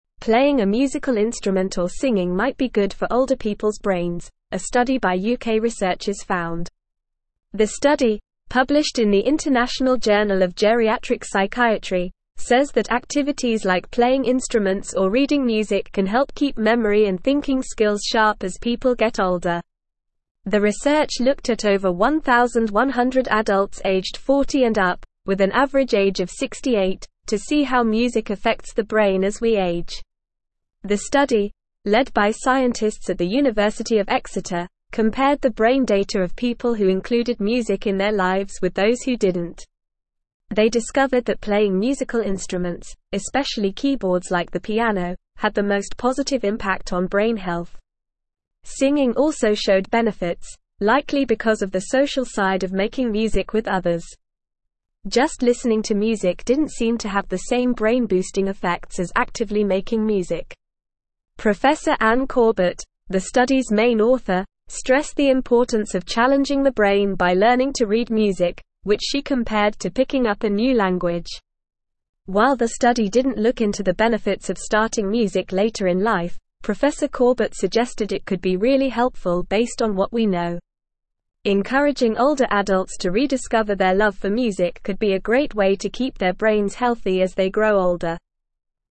Normal
English-Newsroom-Upper-Intermediate-NORMAL-Reading-Music-and-Brain-Health-Benefits-of-Playing-Instruments.mp3